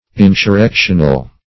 Insurrectional \In`sur*rec"tion*al\, a. [Cf. F.